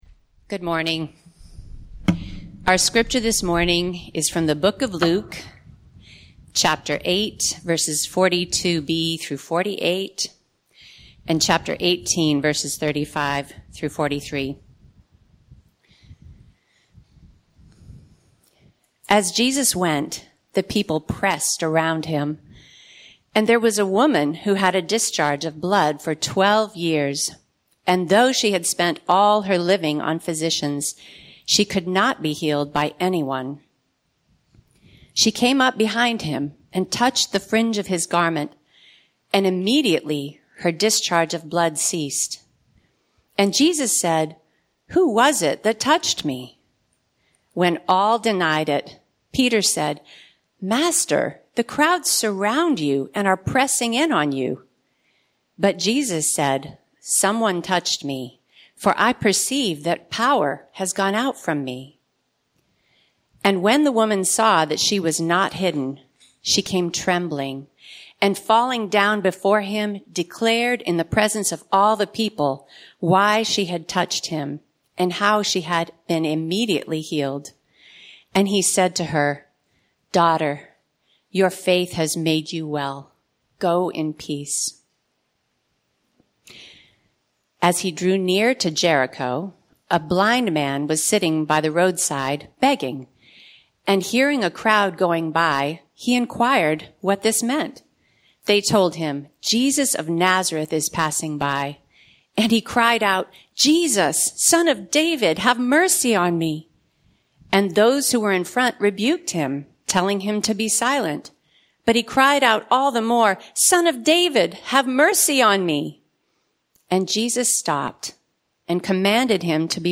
Format: Sermons